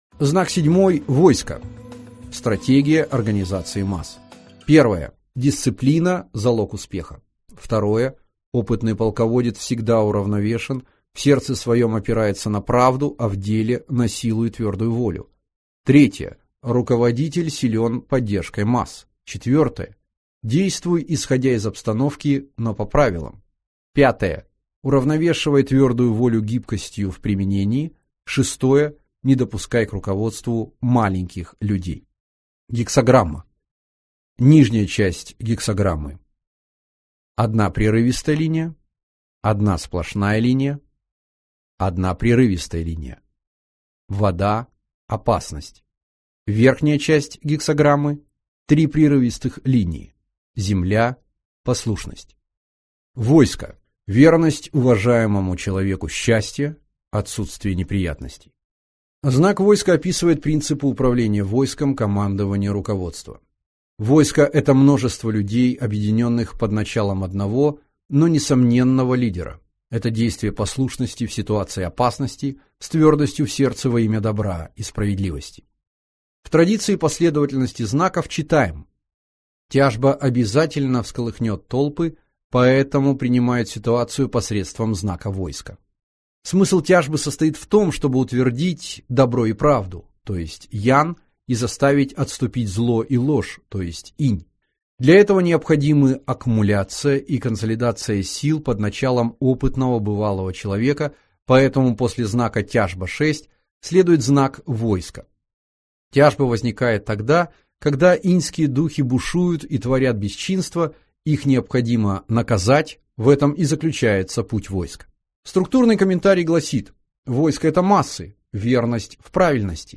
Аудиокнига Практический курс управления переменами. Технология принятия решений по «Книге перемен» | Библиотека аудиокниг